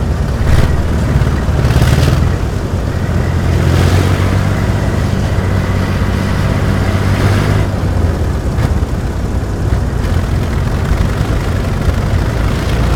tank-engine-2.ogg